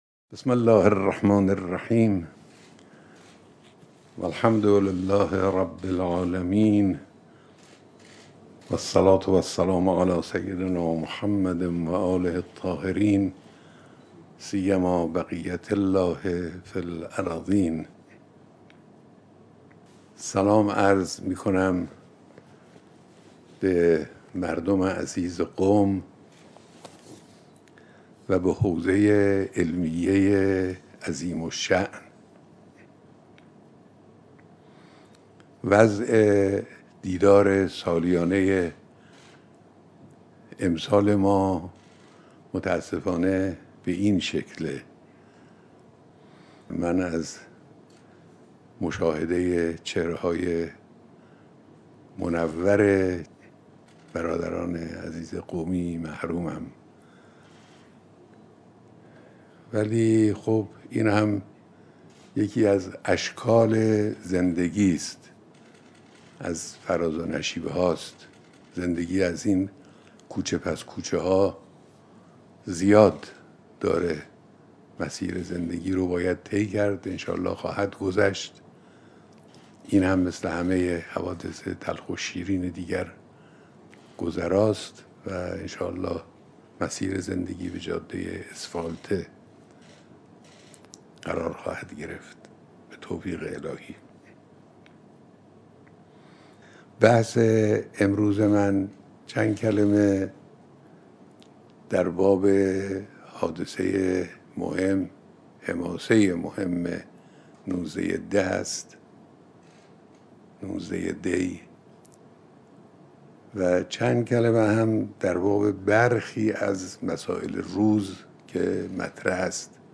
سخنرانی تلویزیونی در سالروز قیام 19 دی 1356 مردم قم